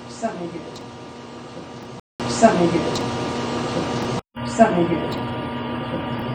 Bar Area
- This is an interesting clear female, her voice trails off and its unclear of what she is saying after it to.